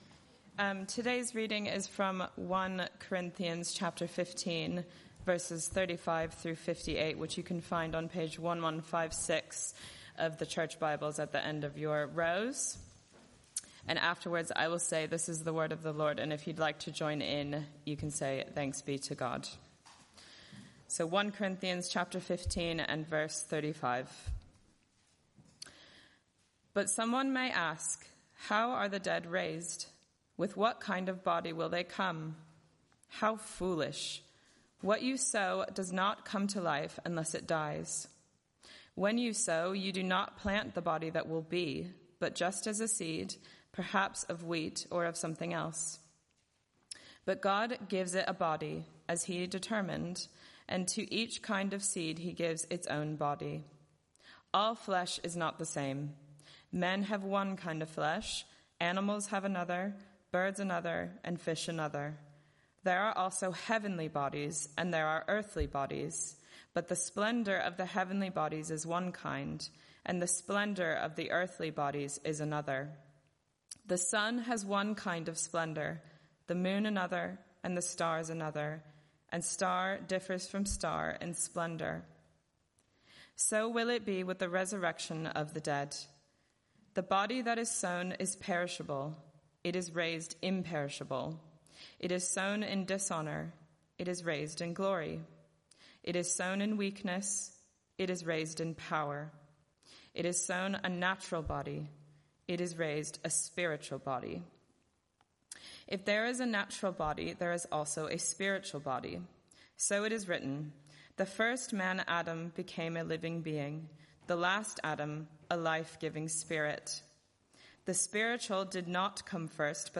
Media for Arborfield Morning Service
Sermon